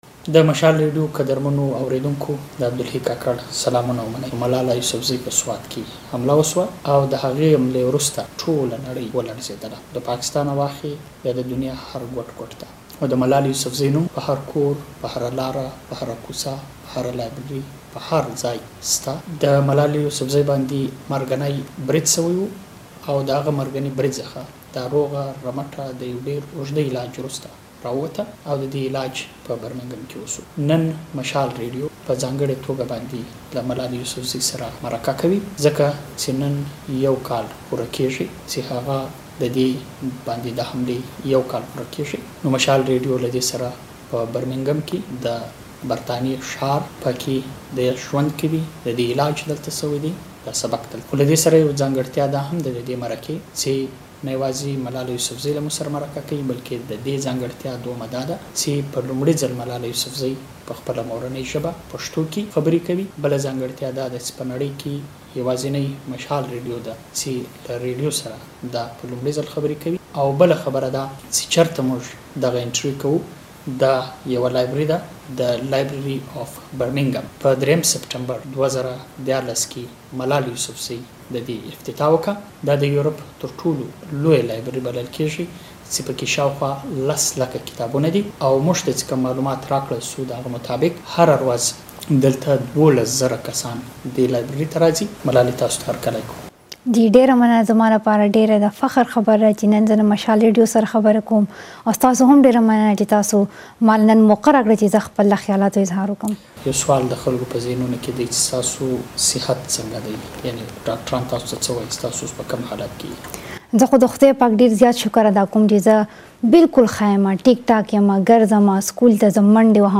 له ملالې یوسفزۍ سره ځانګړې مرکه